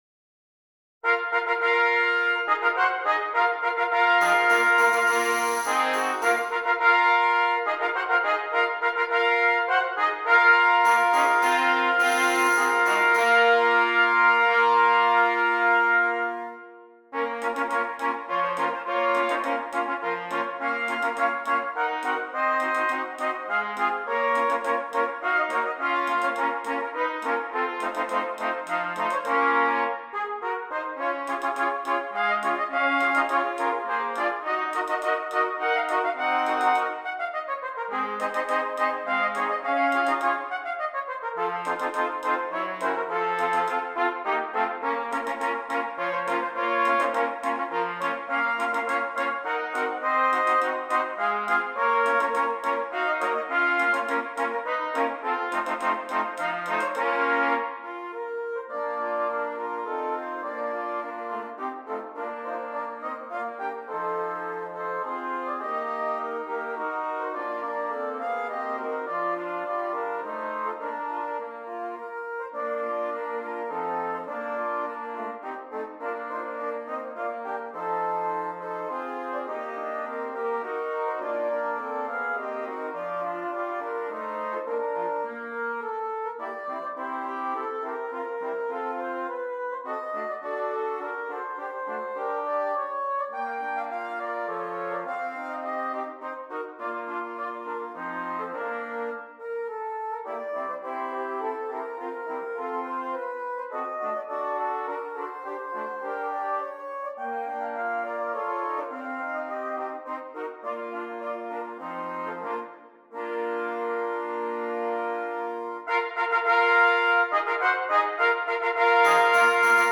6 Trumpets
For 6 trumpets.